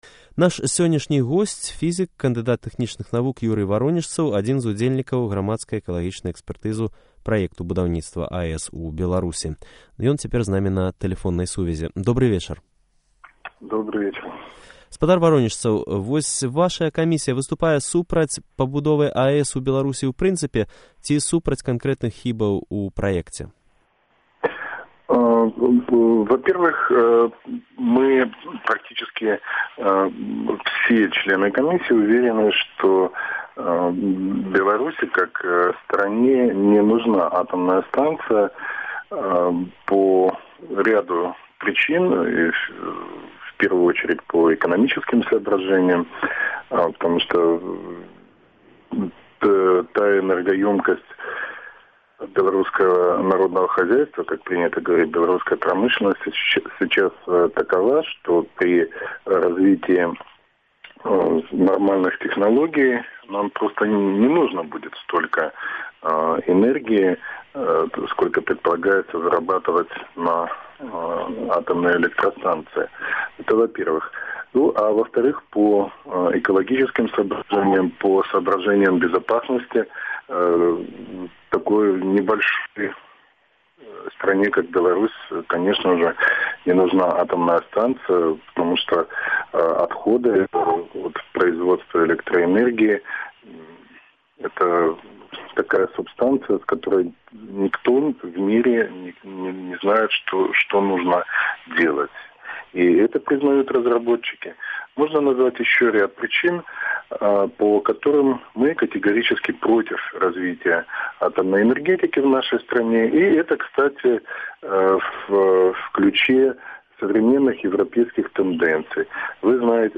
Гутарка з Юрыем Варонежцавым